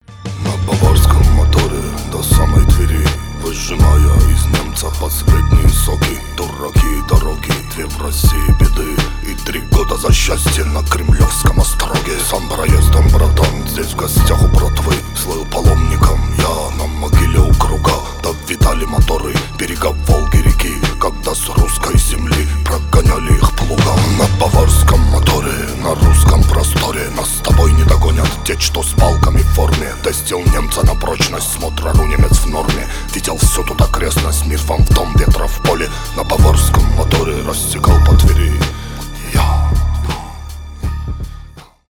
рэп , блатные